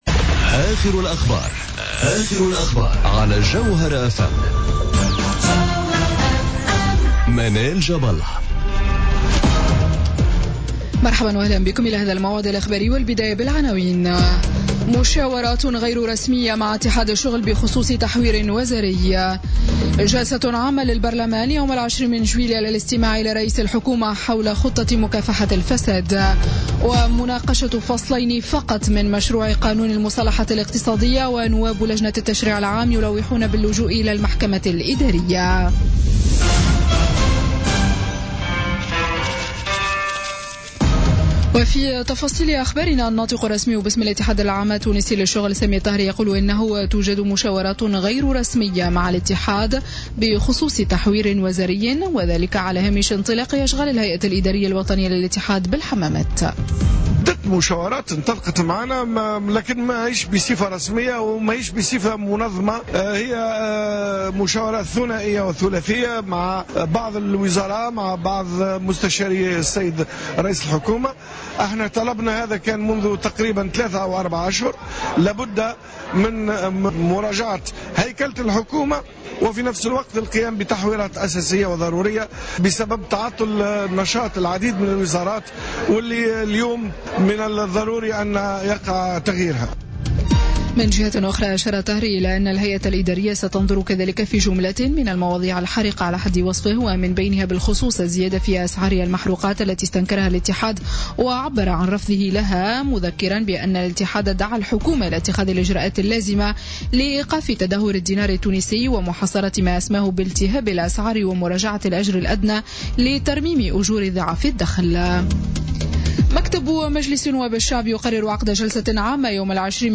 نشرة أخبار منتصف الليل ليوم الجمعة 07 جويلية 2017